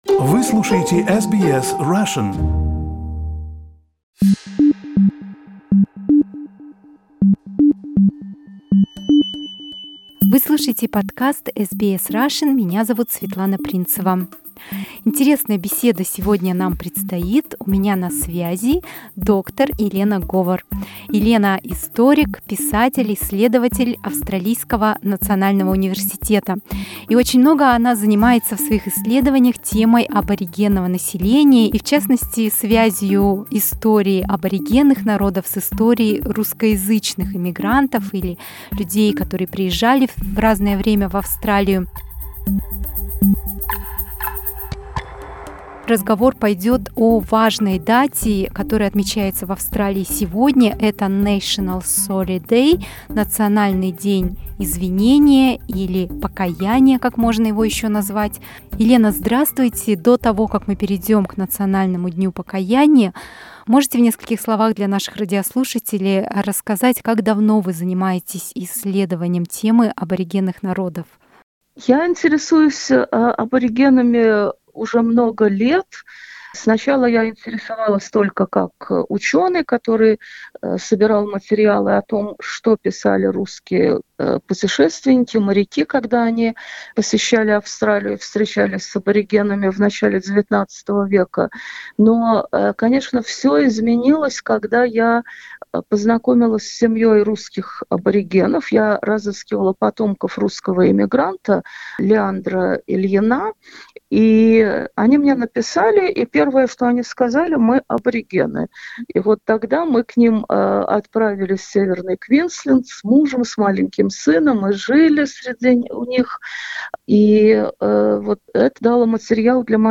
This interview was originally published on May 26, 2021.